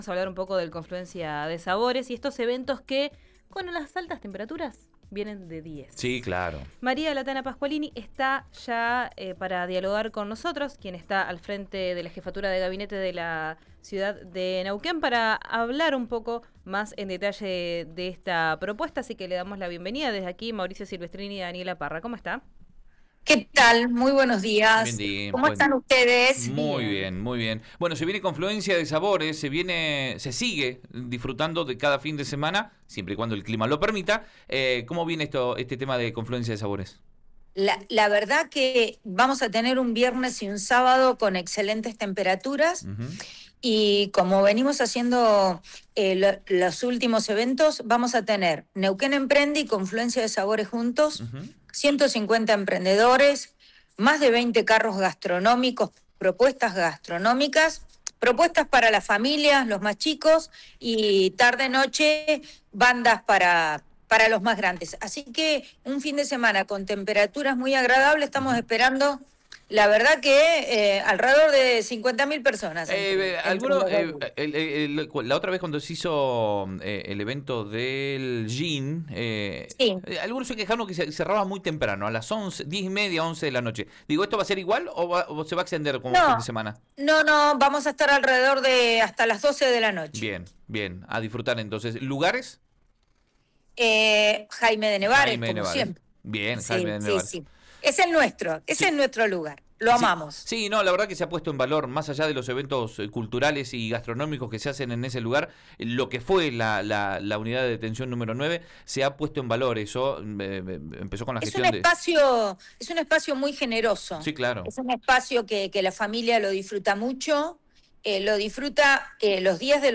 Escuchá a María Pasqualini, jefa de Gabinete de Neuquén, en RÍO NEGRO RADIO: